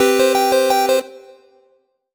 collect_item_chime_04.wav